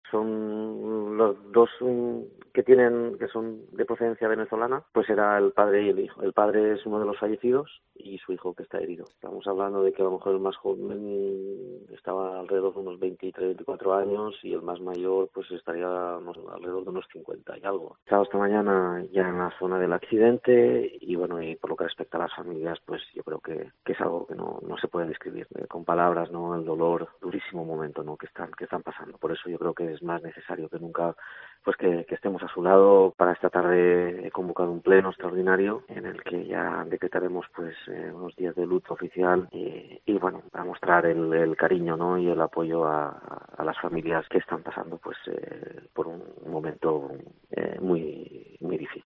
El alcalde de Jávea, José Chulvi, decreta tres días de luto oficial y muestra en COPE el apoyo a las familias